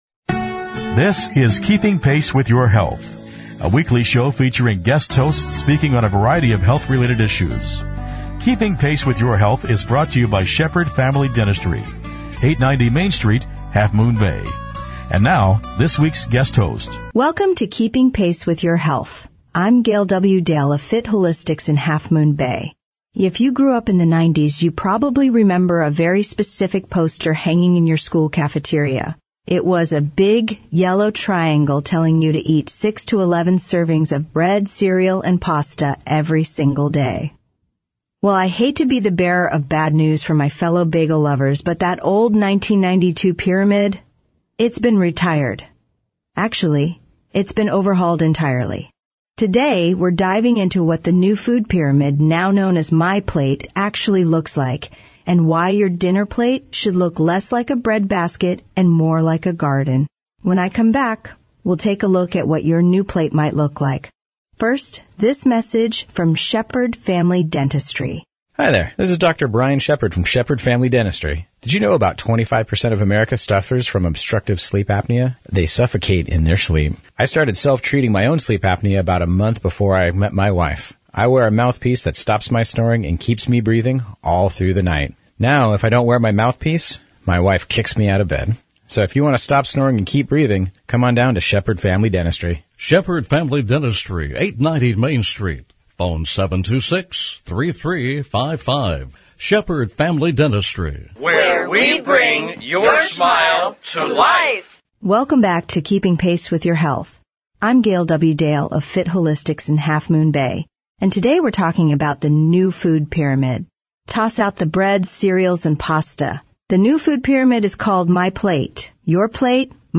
Each week we present a different guest host speaking on a wide range of health related topics.